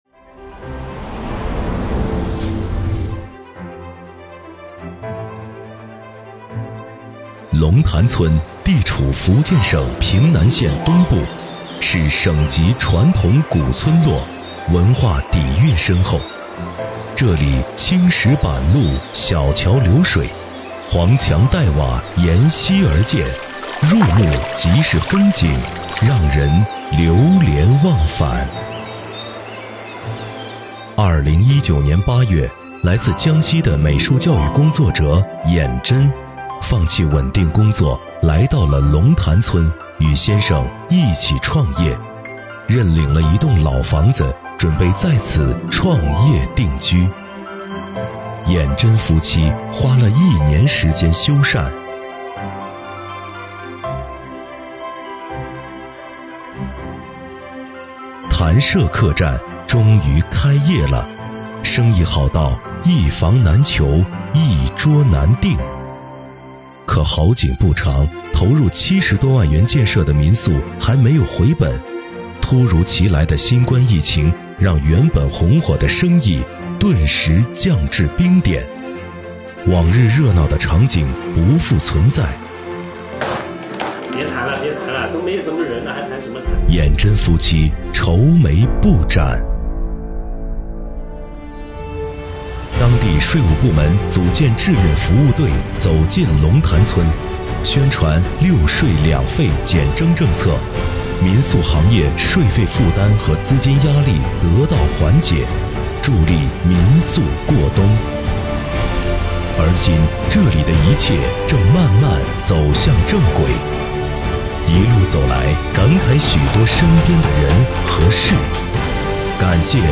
作品通过真实故事场景还原+配音旁白的叙事方式，运用航拍、延时摄影、升格镜头等拍摄手法，使画面极具感染力，展示了税务部门出台一系列帮扶政策及举措，助力文艺青年的创业梦。